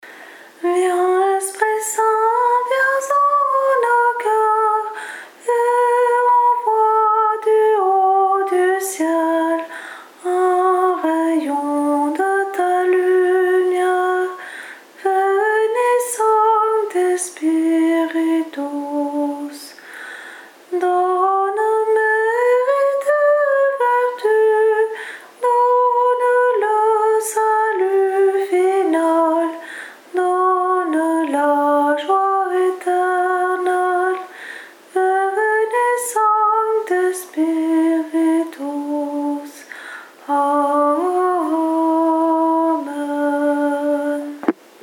Voix chantée (MP3)COUPLET/REFRAIN
SOPRANE